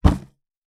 Punching Box Intense H.wav